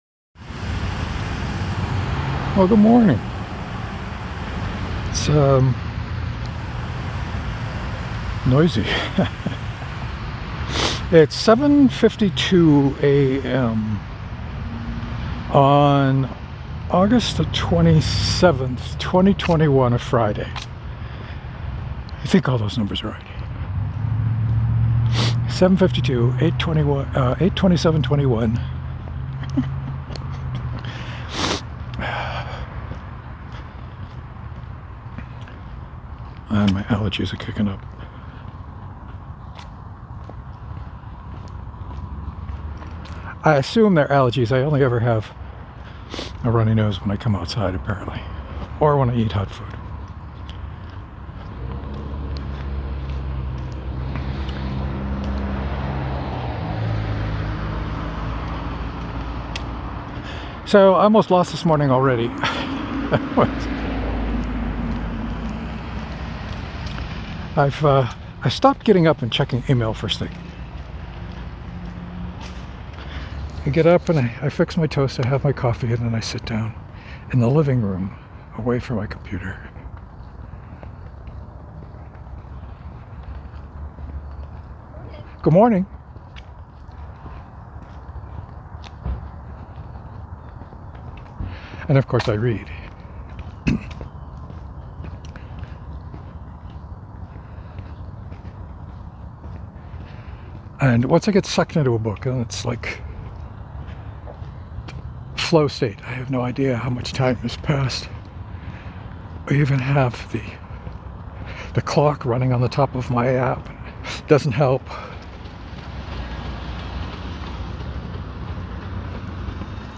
I talked a lot about the games I play today. Like for pretty much the whole walk.